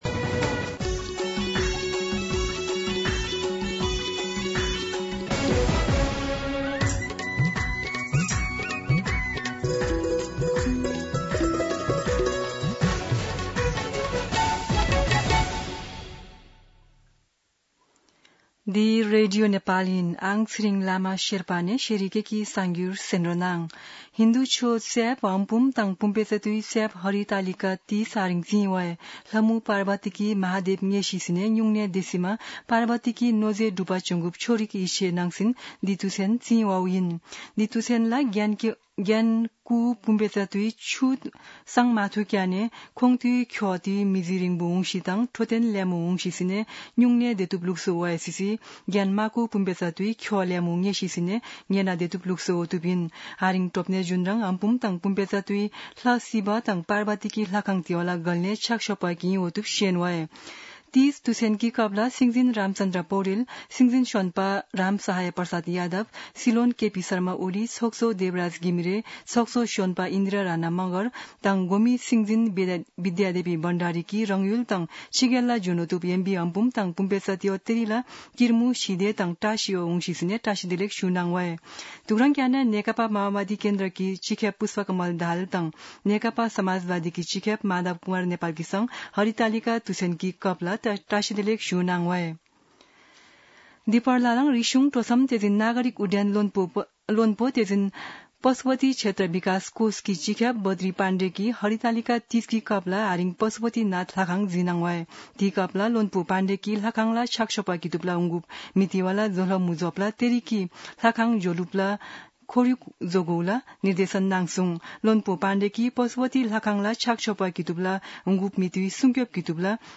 शेर्पा भाषाको समाचार : १० भदौ , २०८२
Sherpa-News-05-10.mp3